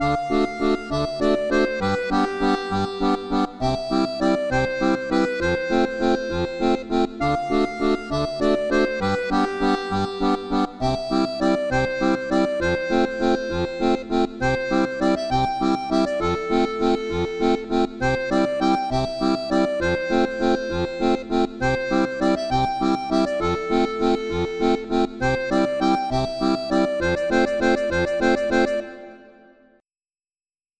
accordéon diatonique
Musique traditionnelle